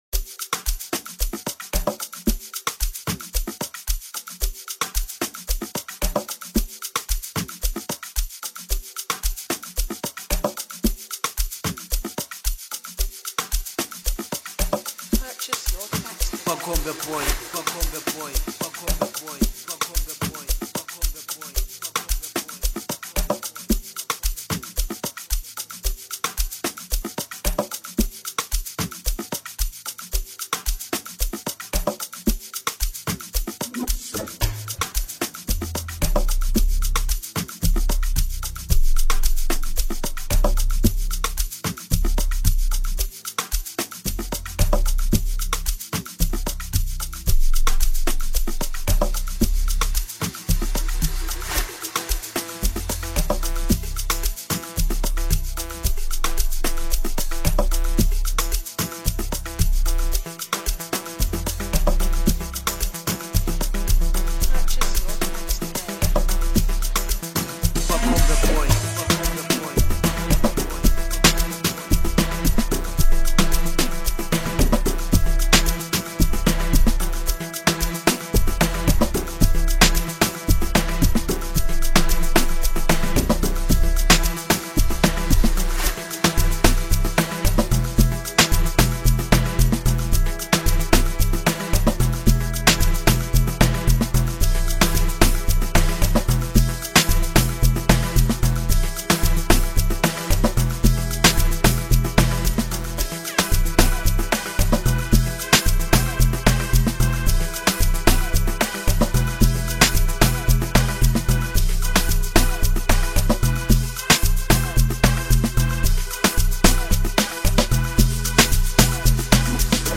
Home » Amapiano » Deep House » Latest Mix